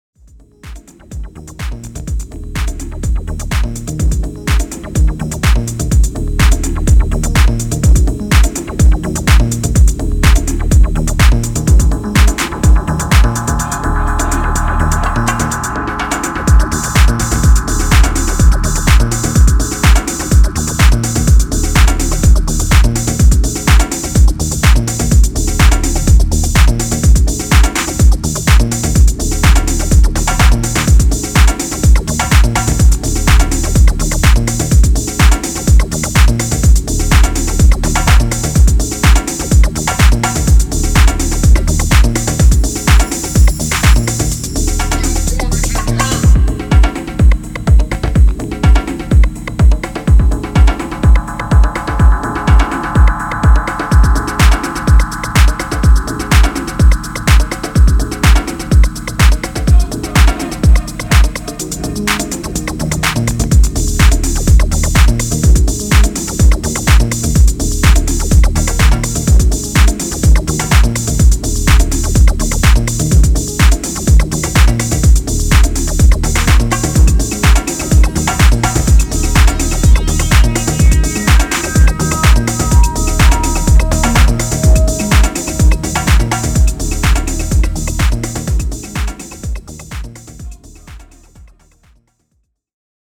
ソリッドなディープ・ハウス群を展開しています。